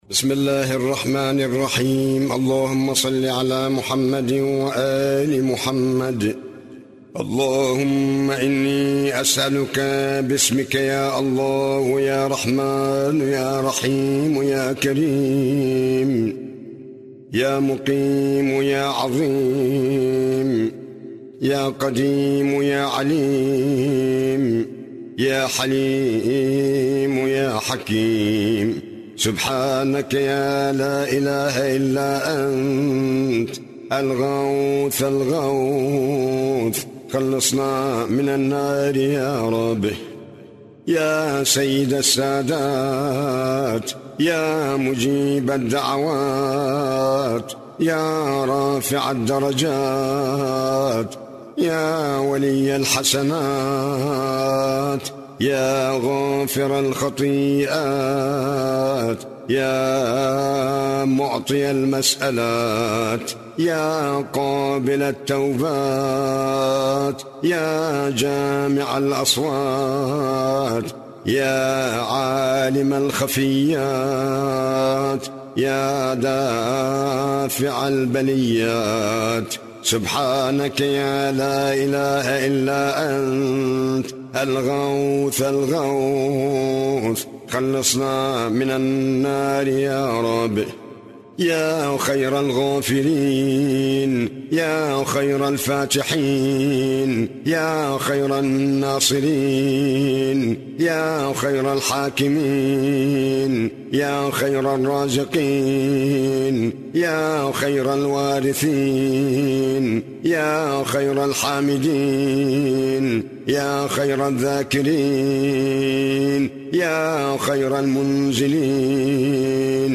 دعاء